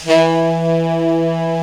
Index of /90_sSampleCDs/Giga Samples Collection/Sax/SAXIBAL
TENOR SOFT E.wav